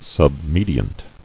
[sub·me·di·ant]